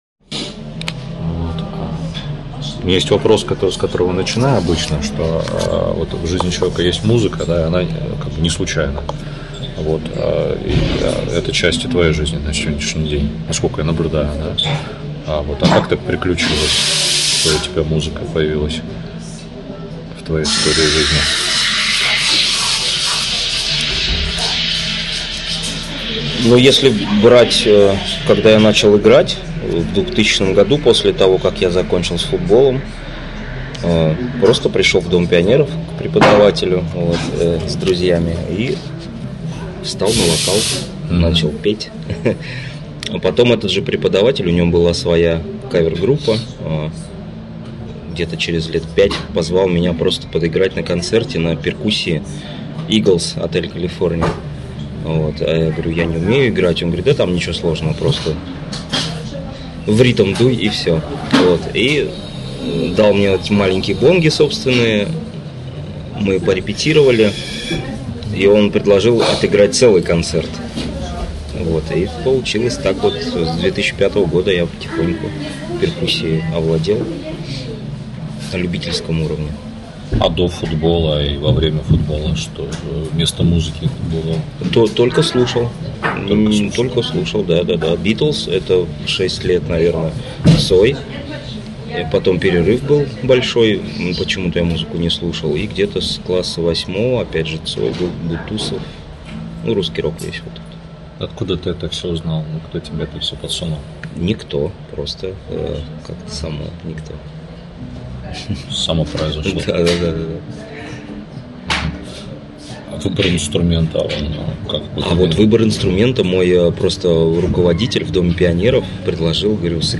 Из интервью